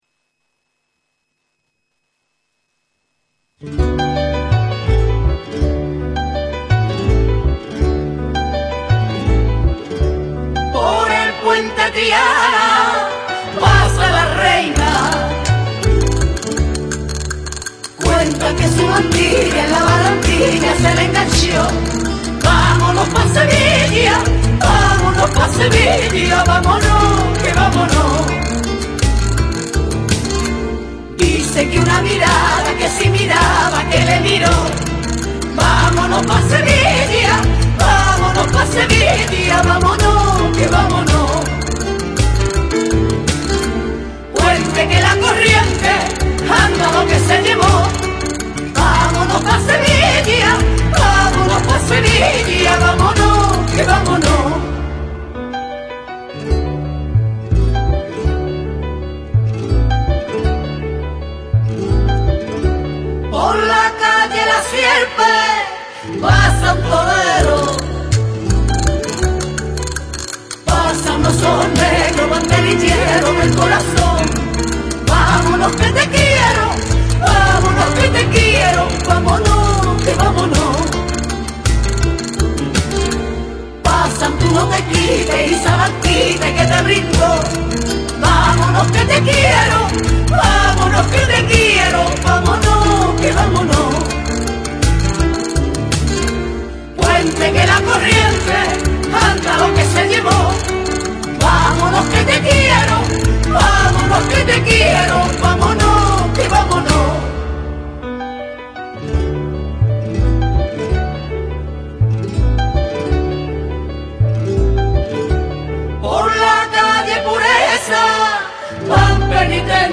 dos hombres y dos mujeres